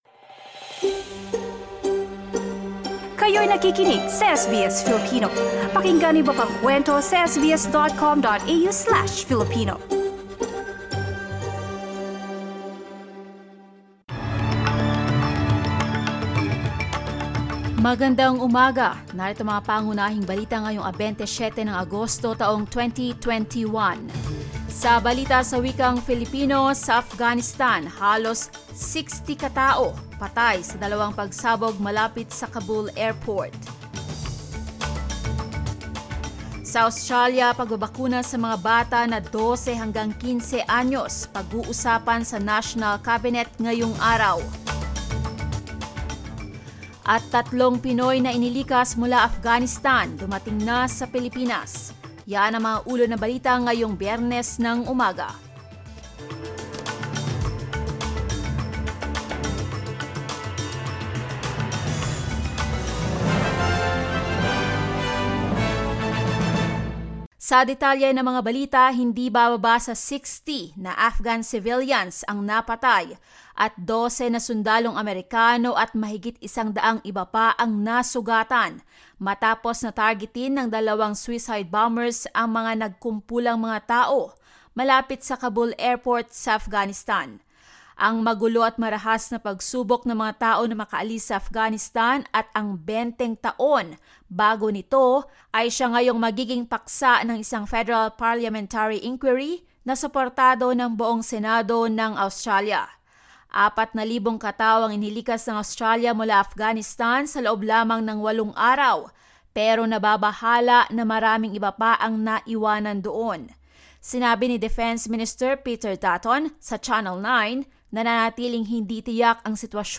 SBS News in Filipino, Friday 27 August